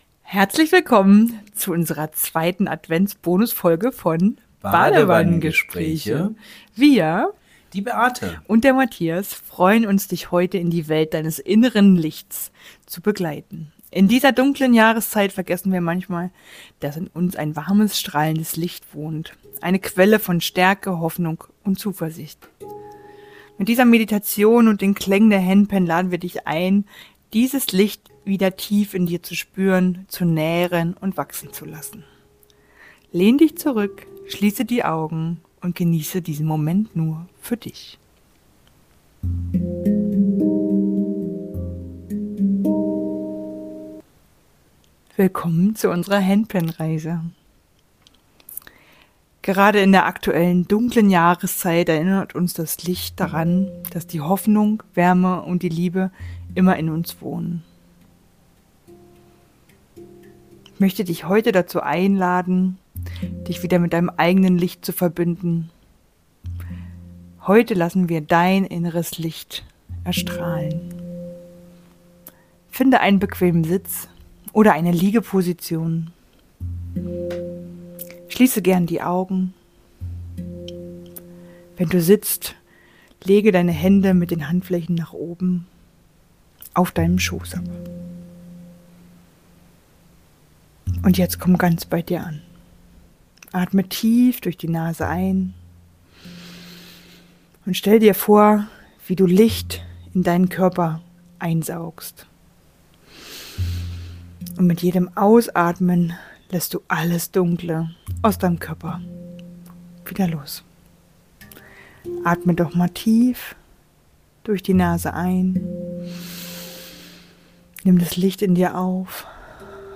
Das Licht in dir - die 2. Handpan-Klangreise